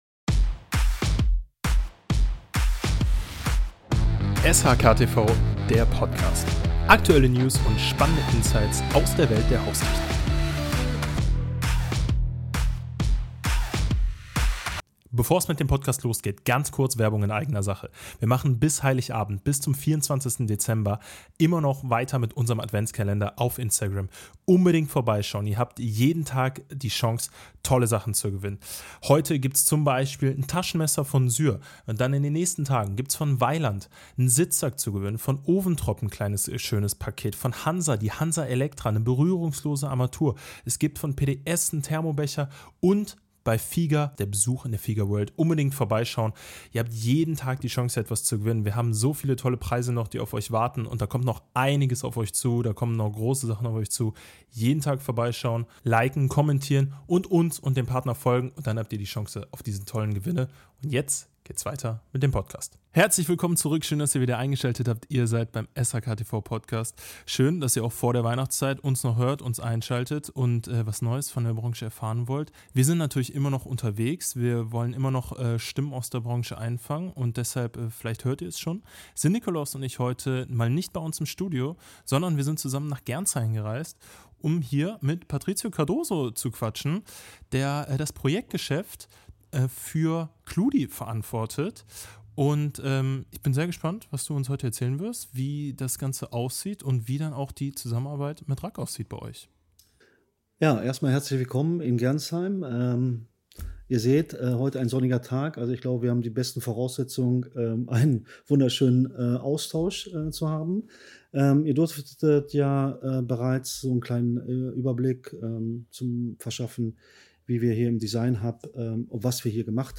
Wir waren in Gernsheim bei KLUDI zu Gast